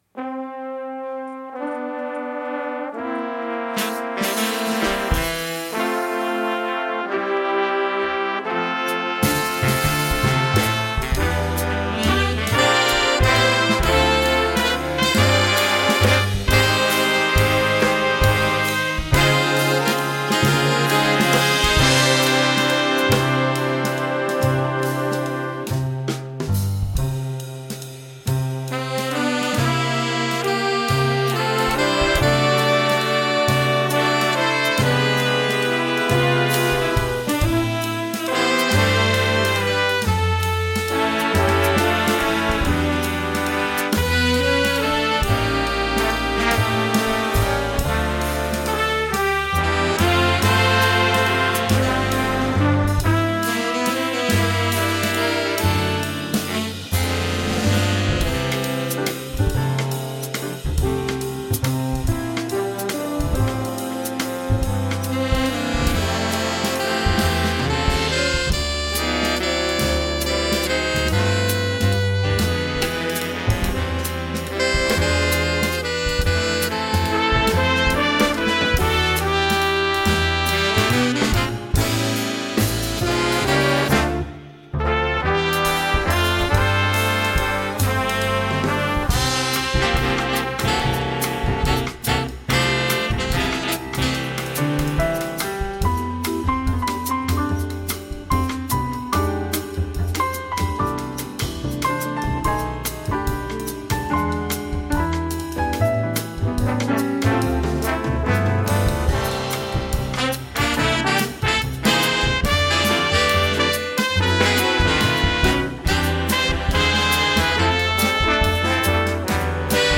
Voicing: Jazz Band